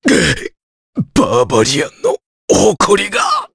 Gau-Vox_Dead_jp.wav